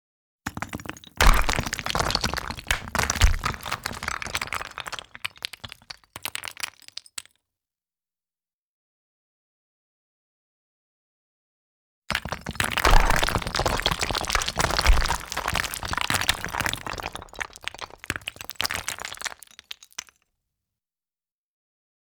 Звуки камня
Шум падающих камней